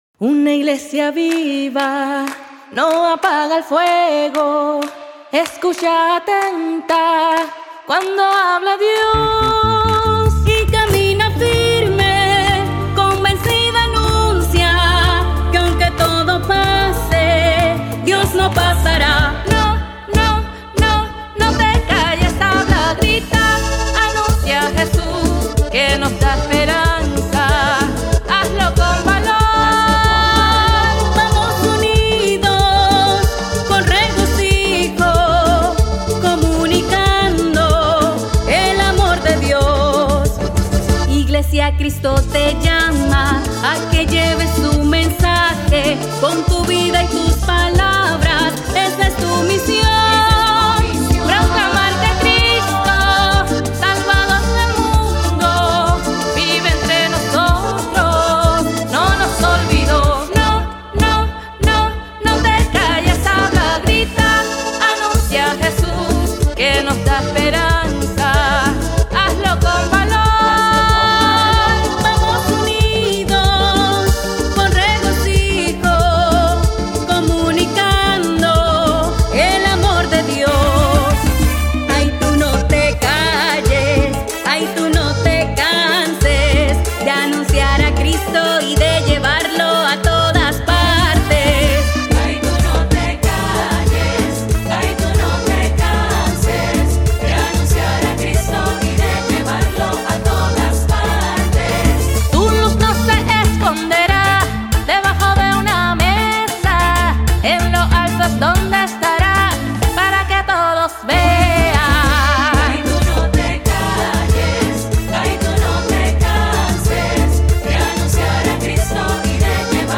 Congas, timbales y güiro
Guitarras
Bajo
Teclados